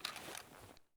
rattle